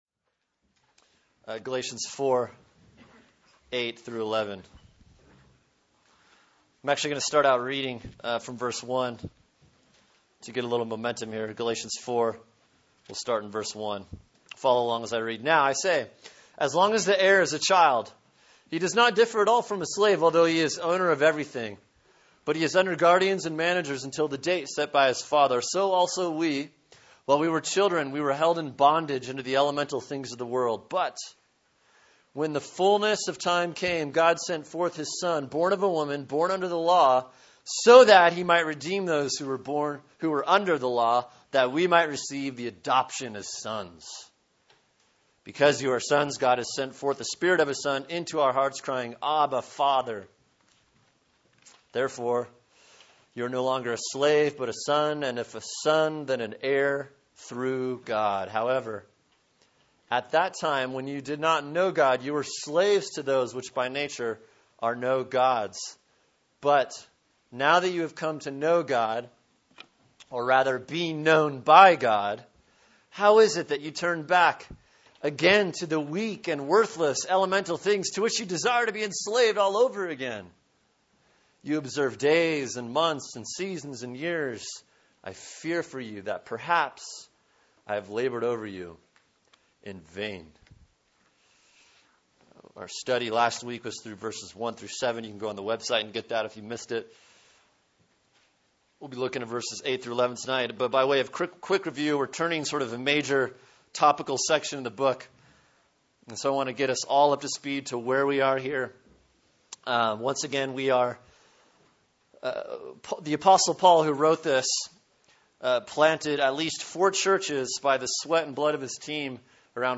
Sermon: Galatians 4:8-11 “Returning To Slavery” | Cornerstone Church - Jackson Hole